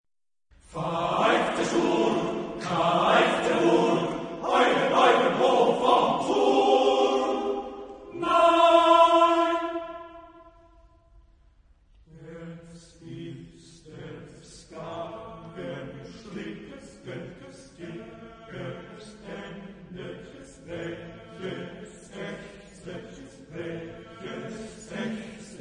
Epoque : 20ème s.
Genre-Style-Forme : Pièce chorale ; Cycle ; Profane
Type de choeur : TTBB  (4 voix égales d'hommes )
Tonalité : libre